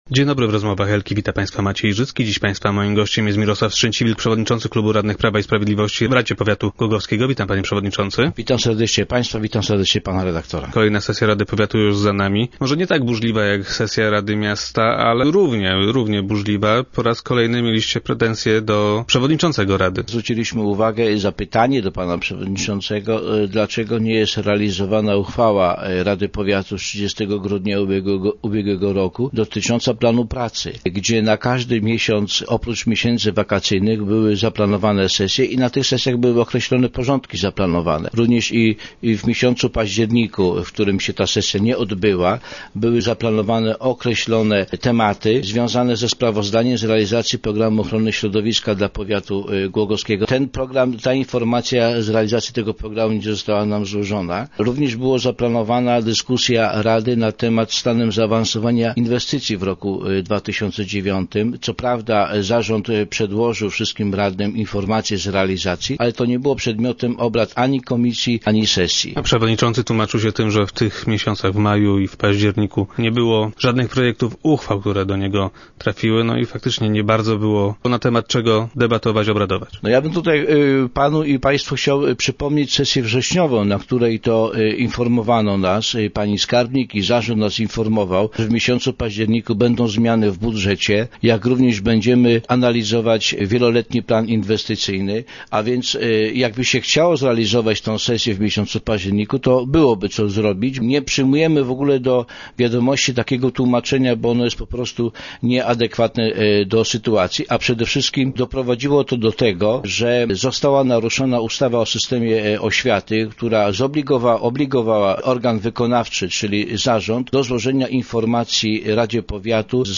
Jak powiedział dzisiejszy gość Rozmów Elki, radni nie mają zastrzeżeń co do samego WPI.